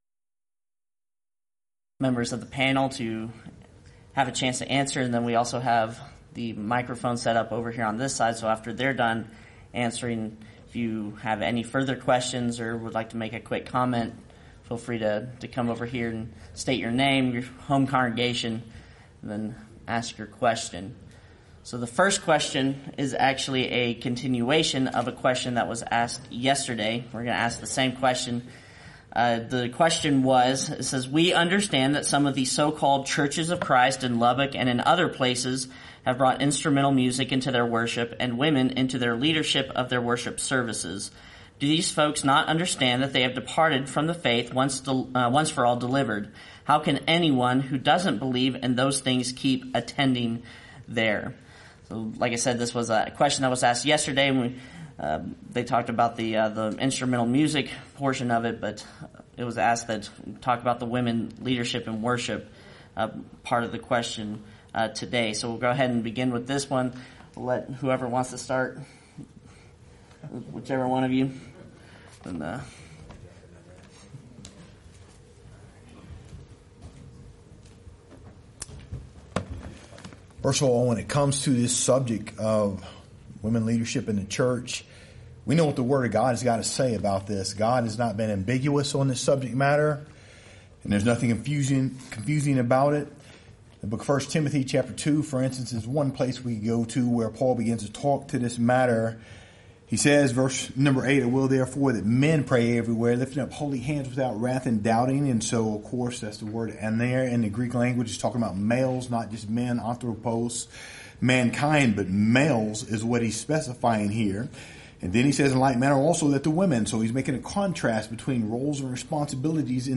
Title: Wednesday Open Forum Speaker(s): Various
Event: 26th Annual Lubbock Lectures Theme/Title: God is Love